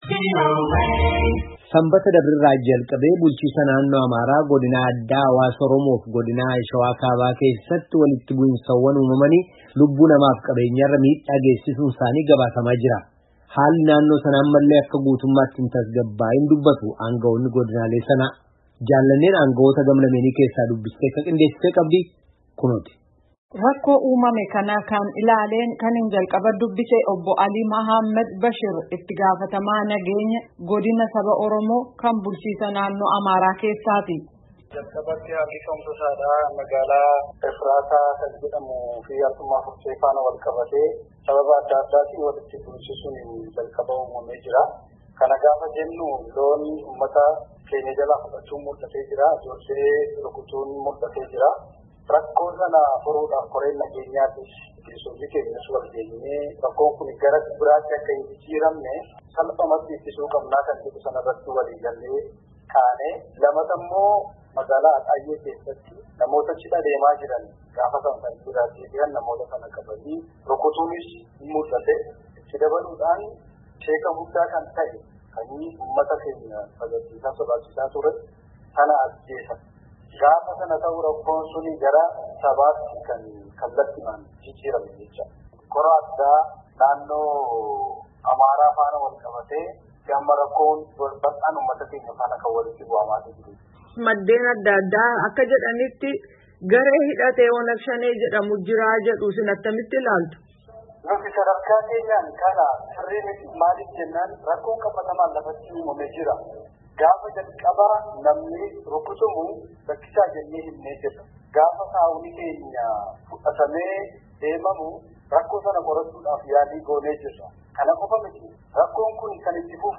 Aangowoota gam lameenii haasoofsisuun kan qindeessinee Caqasaa.